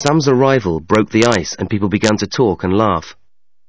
- نطق arrival هو /ərīvəl/
- نطق and هو /and/ أو /ənd/ أو /ən/  من أجل ذلك يصعب أحياناً سماع and .
- الكلمات التي باللون الأحمر ستسمعها بوضوح.
- أما الكلمات أو المقطع التي باللون الرمادي فبالكاد ستسمعها.